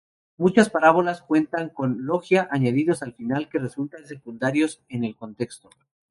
Read more Adj Noun Noun Frequency A1 Hyphenated as fi‧nal Pronounced as (IPA) /fiˈnal/ Etymology From Latin fīnālis.